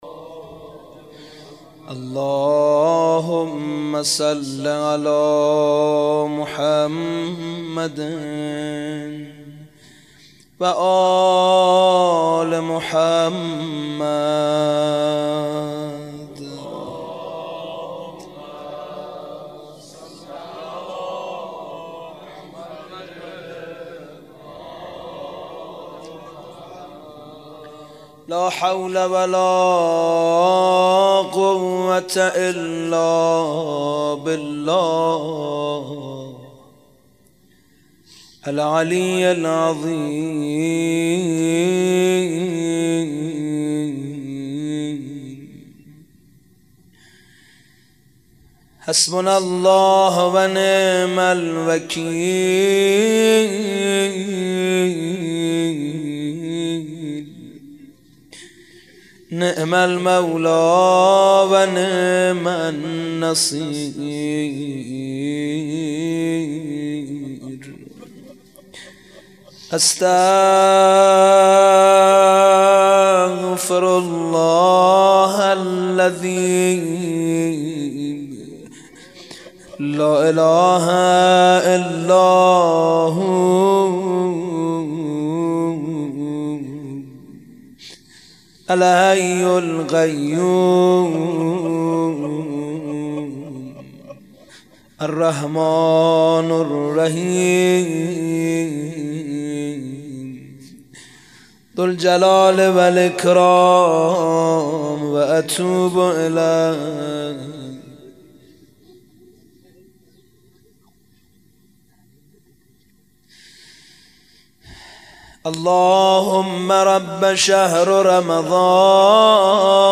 روضه و مناجات